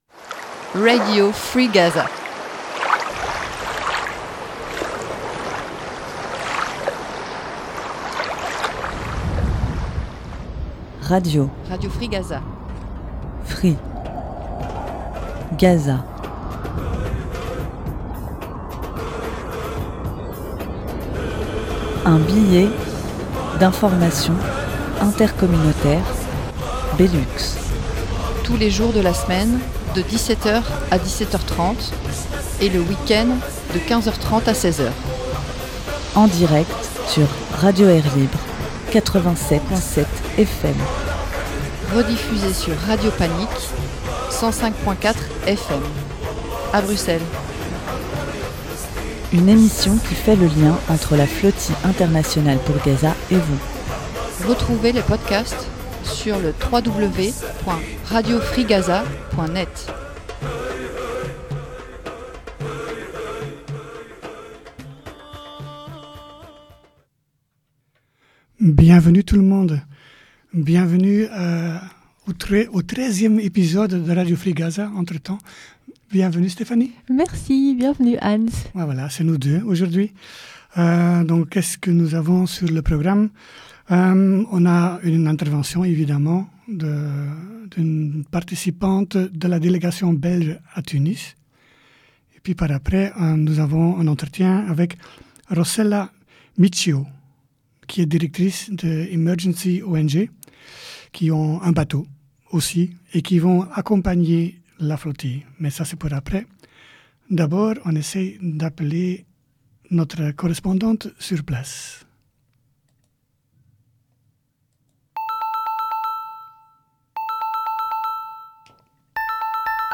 Comme tous les jours nous avons eu un direct avec un.e participant.e de la délégation belge et luxembourgeoise de la flotille.